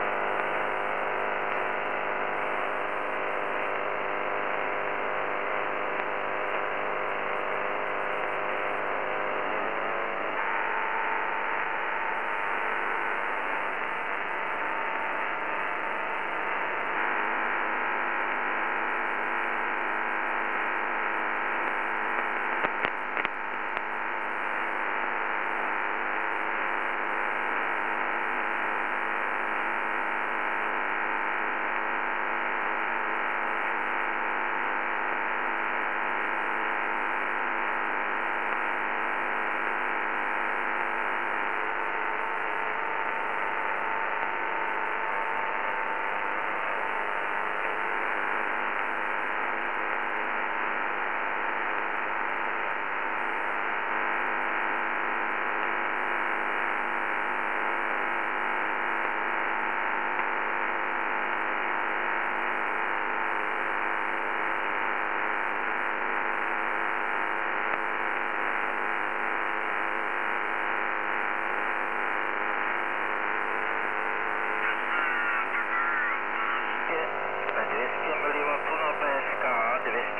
200mW PSK31 (wav)